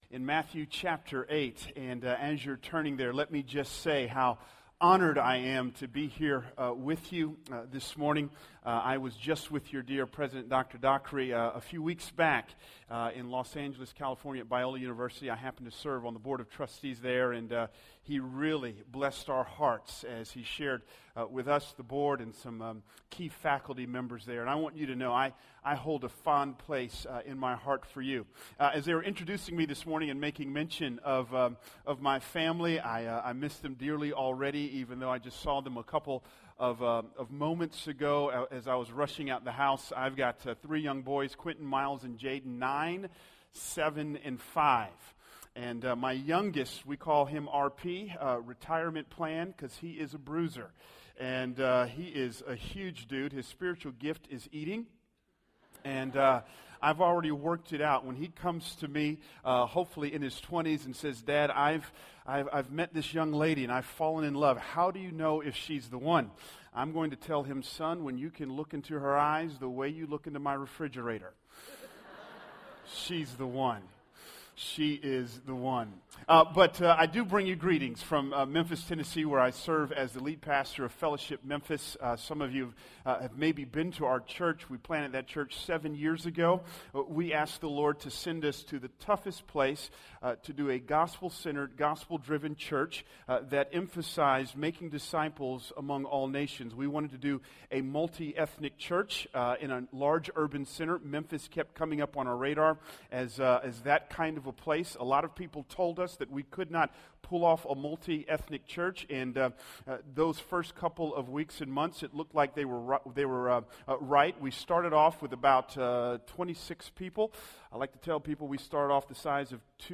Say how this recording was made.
Faith in Practice Chapel